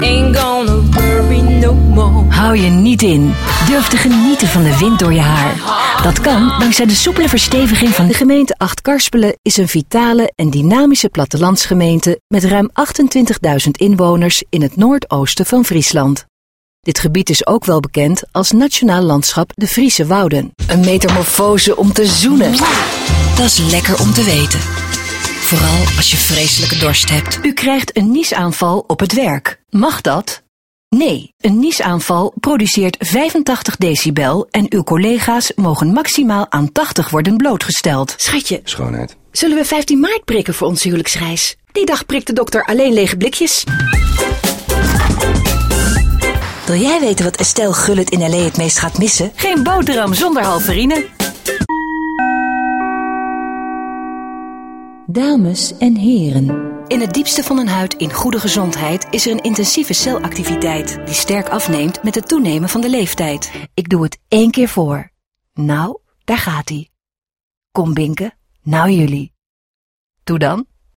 Dutch female voice over
Sprechprobe: Werbung (Muttersprache):